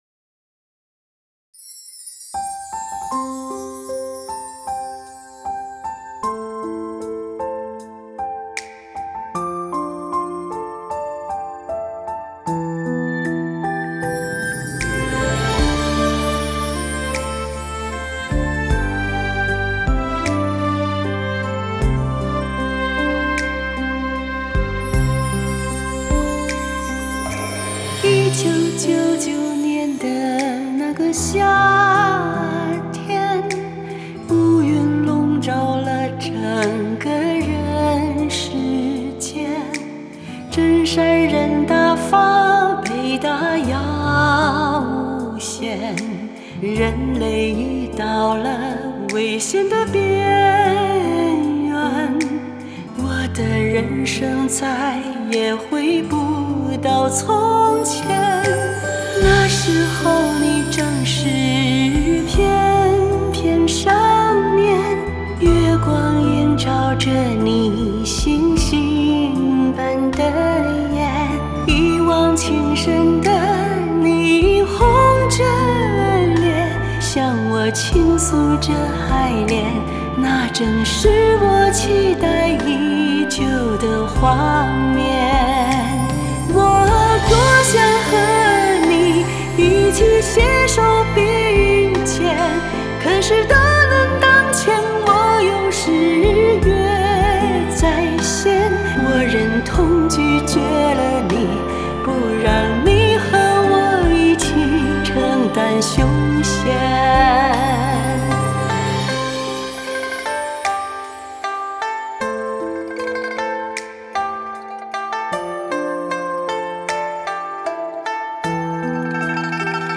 女聲獨唱：月下少年 | 法輪大法正見網